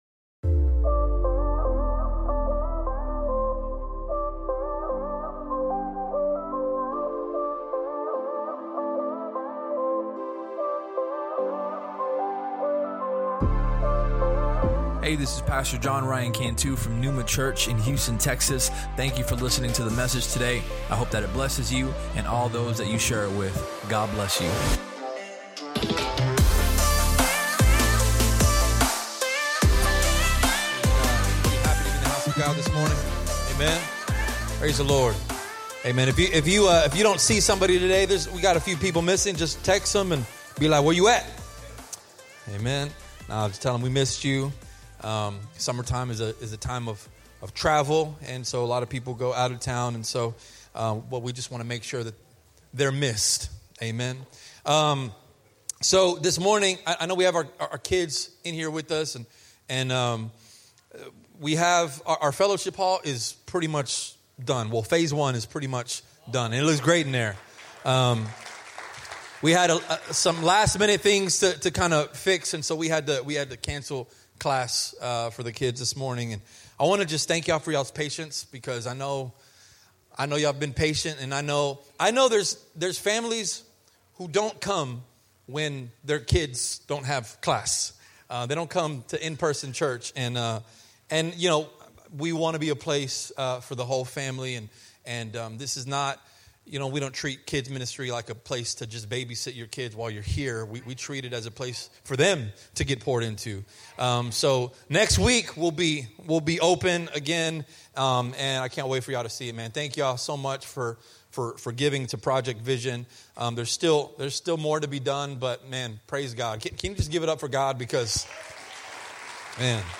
Sermon Topics: Obedience, Sacrifice If you enjoyed the podcast, please subscribe and share it with your friends on social media.